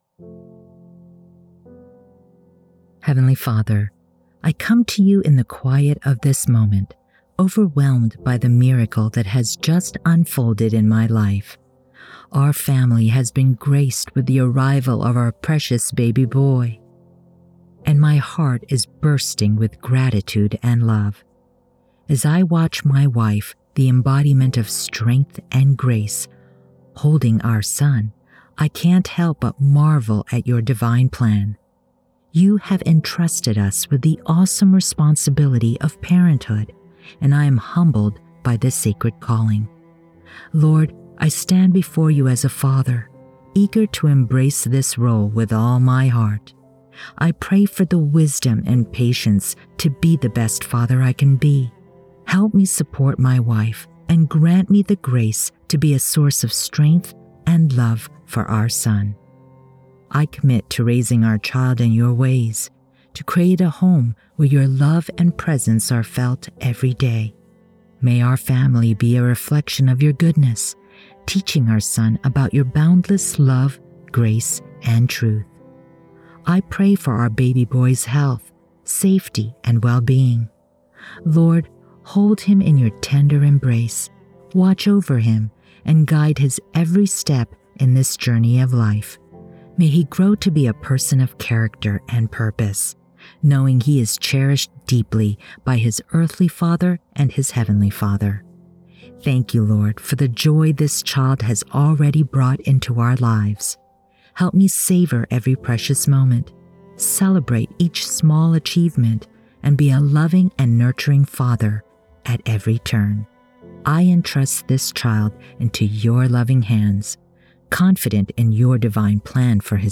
PrayerNewDad-FIXED-WITH-BG-MUSIC-1.wav